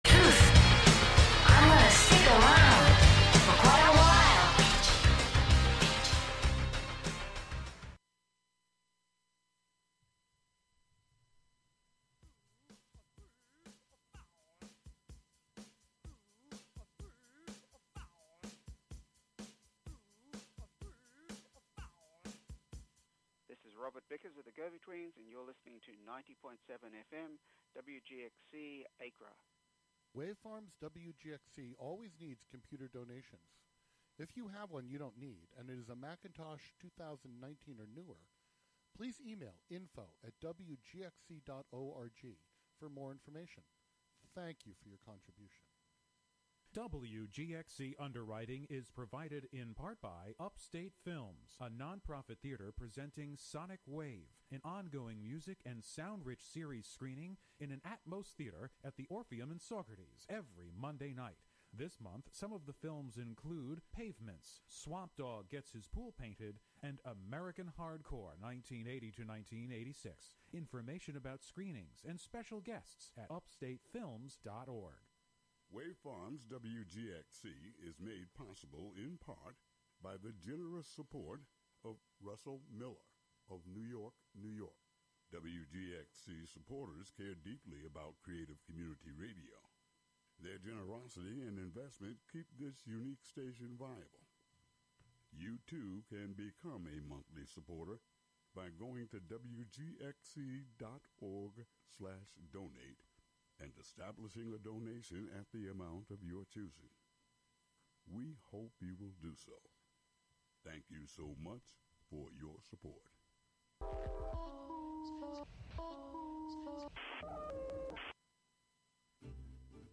An upbeat music show featuring the American songbook